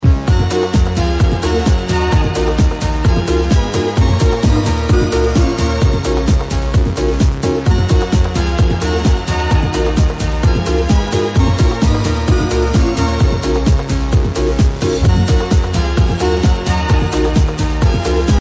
id this house tune (sample inside)